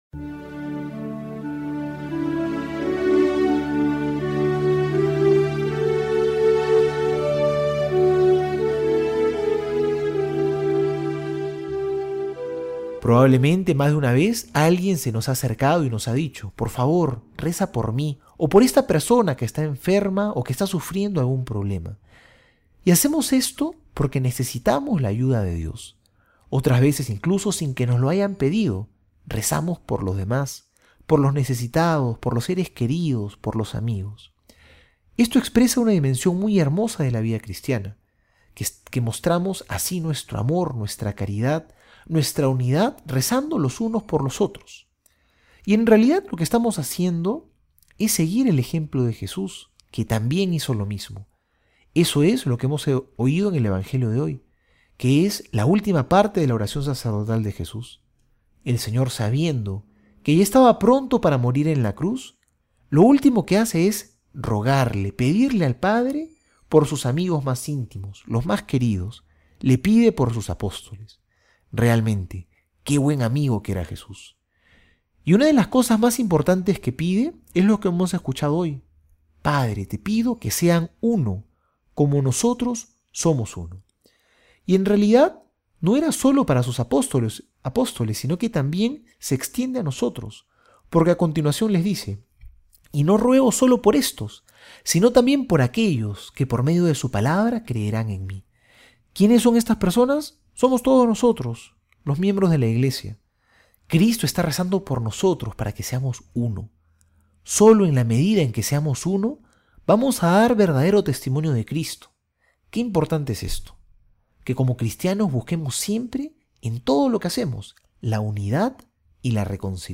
Homilía para hoy:
jueves homilia.mp3